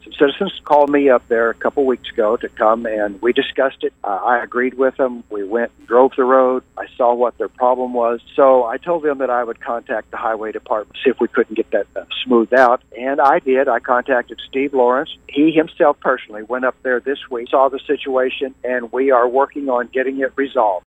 Representative Jack Fortner sat down with KTLO, Classic Hits and The Boot News to shed some light on the situation.